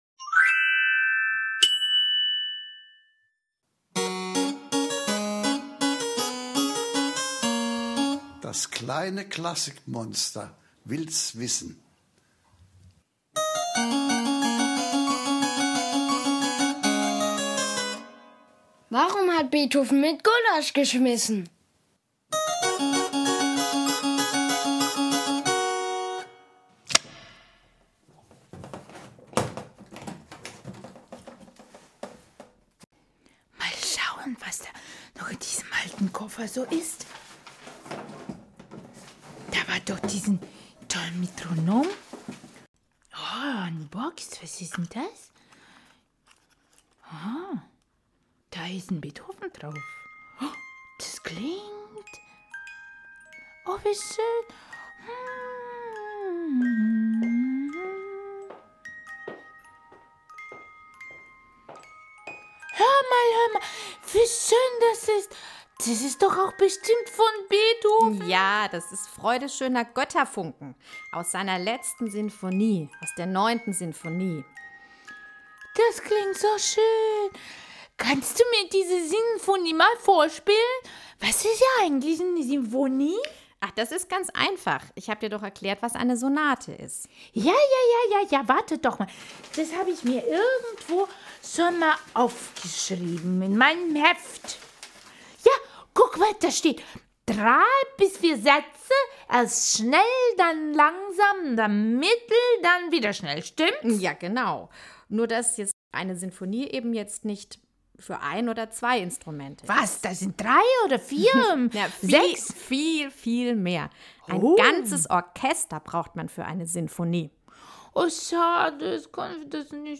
Dezember 2021 Nächste Episode download Beschreibung Teilen Abonnieren Ganz begeistert hört das kleine Klassikmonster Ausschnitte aus Beethovens berühmtesten Sinfonien. Da darf die Ode an die Freude natürlich nicht fehlen.